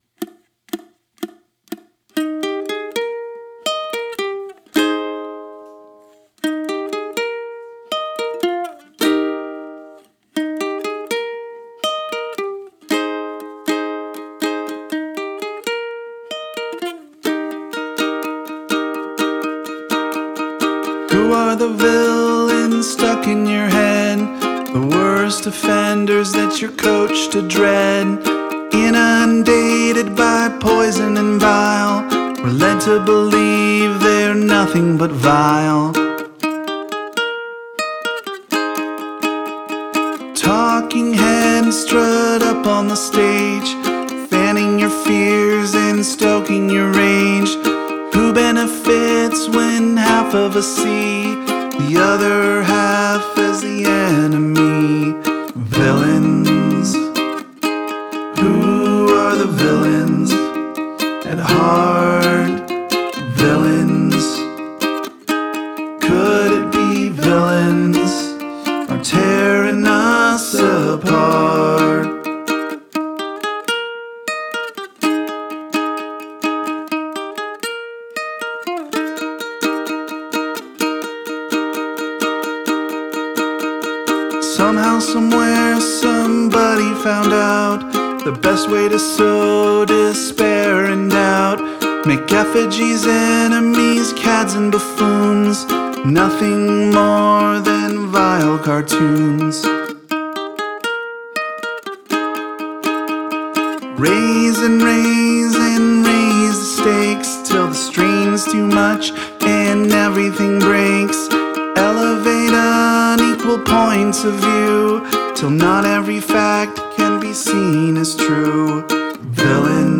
Musically, the songs is fairly diatonic. I can see the chorus as either a quick trip to the relative minor (C minor), or as staying in the original Eb major key and just being pulled in a minor direction.
This was a live recording, using my Zoom H6 and its X/Y pair on the ukulele, and a Shure SM-57 on my vocals. The only overdubs are the harmony vocals.